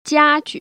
[jiā‧ju]